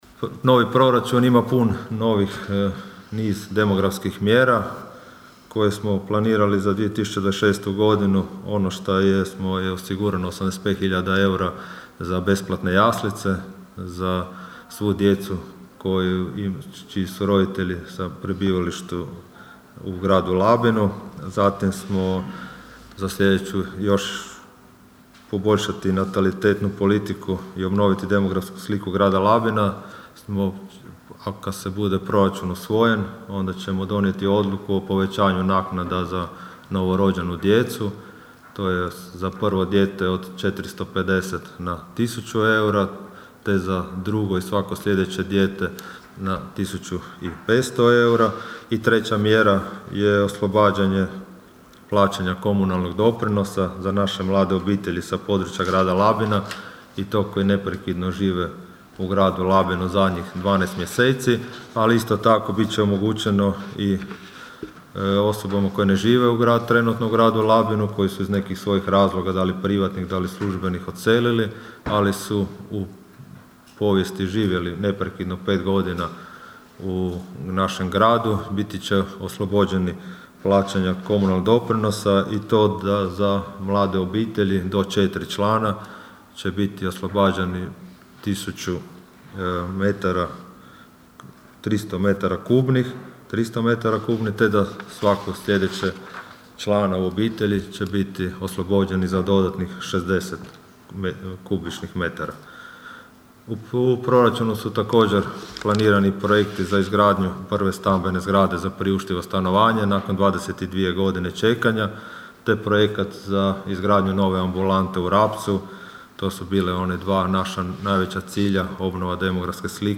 "U ovih šest mjeseci uspjeli smo stabilizirati financije i proračun, a nakon što nas je kad smo preuzeli vlast dočekala upitna likvidnost i problemi s plaćanjem prema dobavljačima", kazao je danas na konferenciji za novinare, govoreći o prvih šest mjeseci mandata i najavljujući proračun za iduću godinu, gradonačelnik Labina Donald Blašković.
ton – Donald Blašković), rekao je gradonačelnik Blašković.